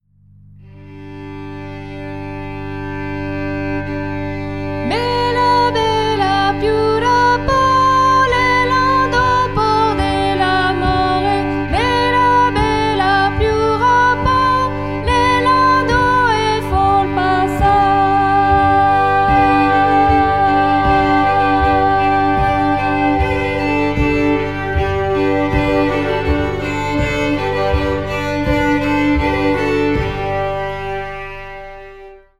- La Carabotine : chants de montagne accompagés par des violons : extrait chanson de la mariée